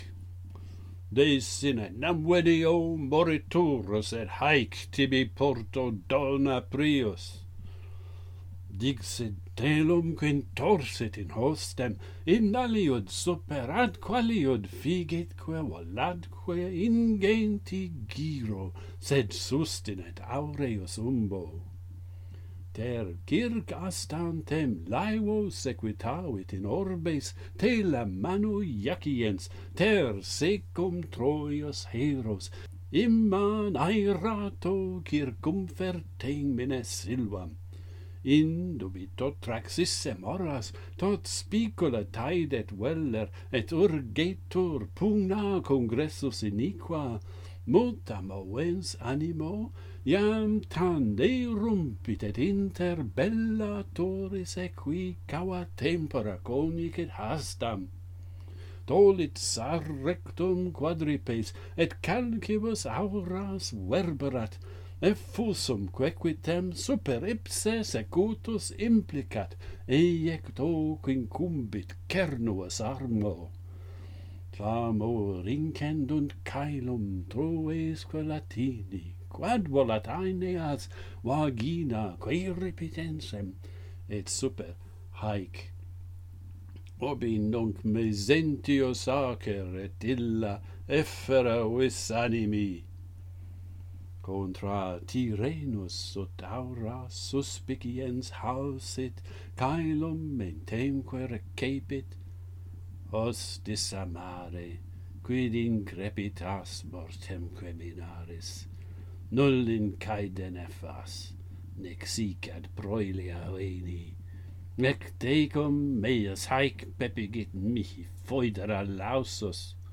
King Mezentius meets his match - Pantheon Poets | Latin Poetry Recited and Translated